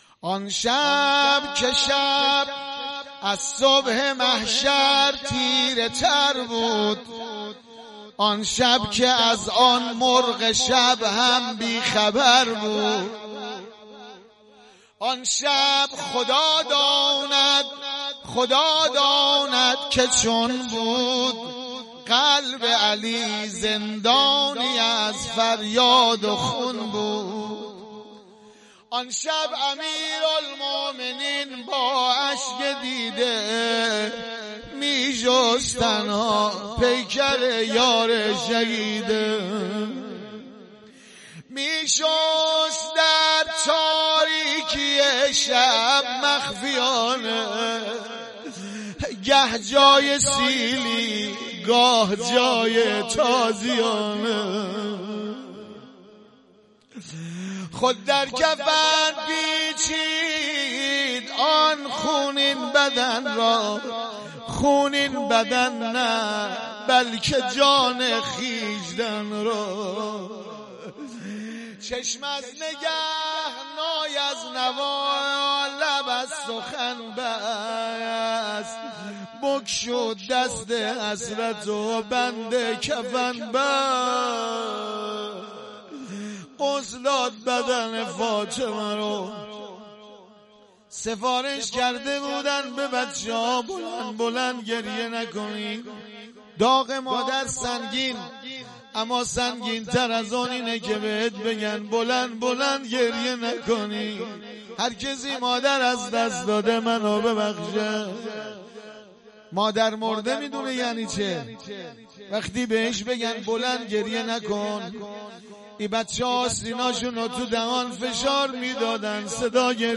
روضه و سینه زنی پایان جلسه.mp3